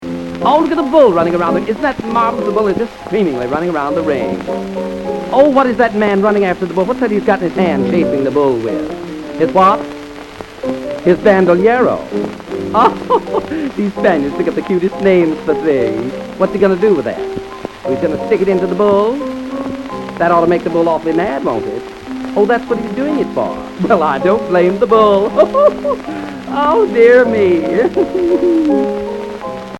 piano accompaniment